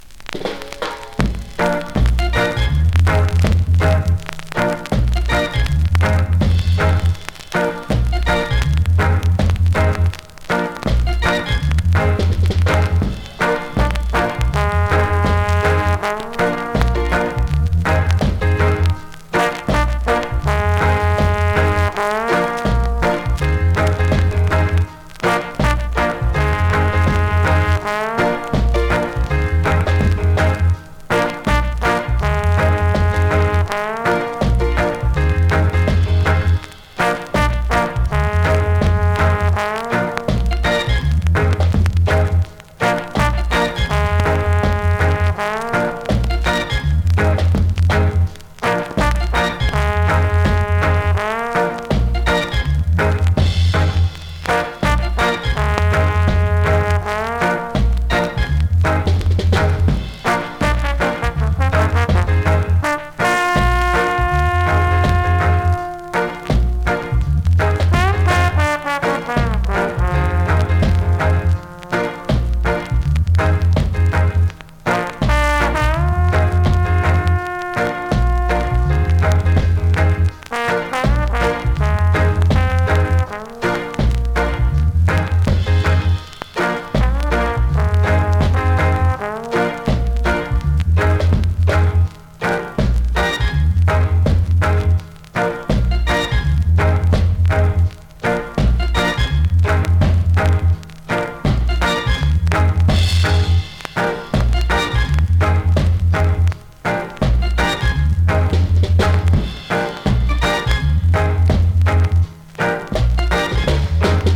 スリキズ、ノイズそこそこあります。